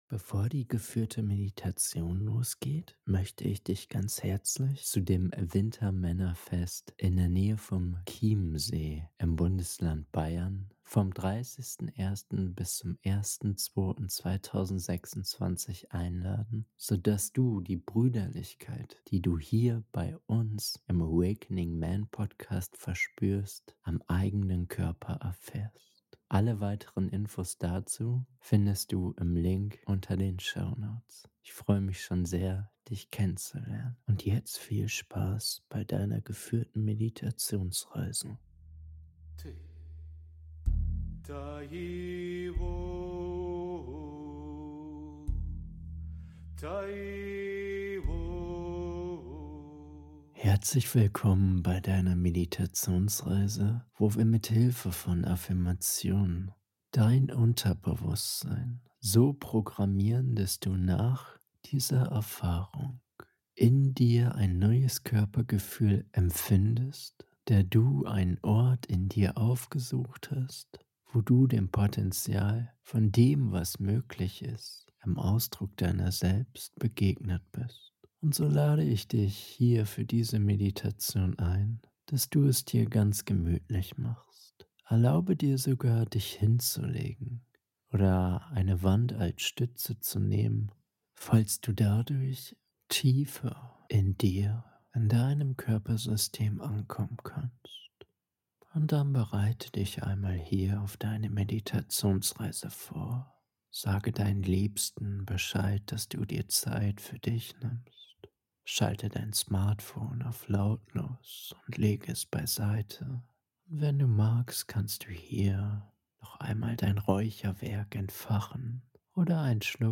Geführte Meditation mit positiven Affirmationen ~ AWAKENING MEN Podcast